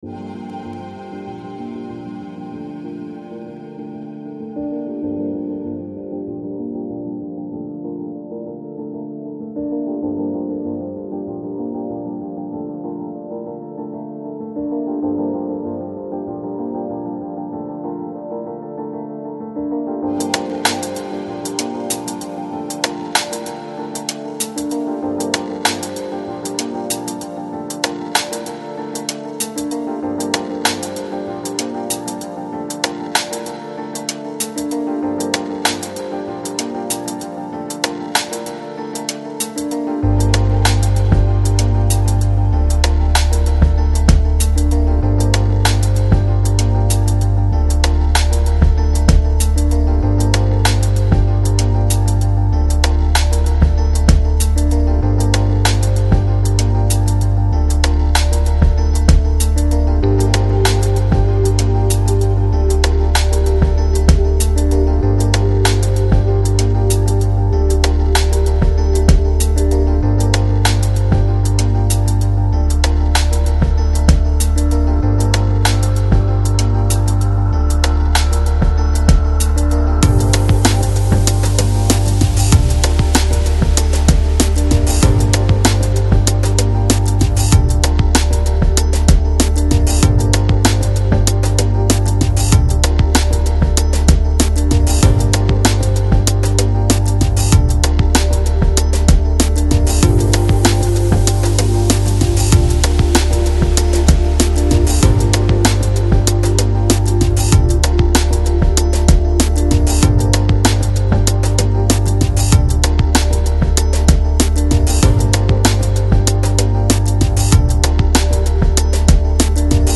Жанр: Lounge, Chill Out, Downtempo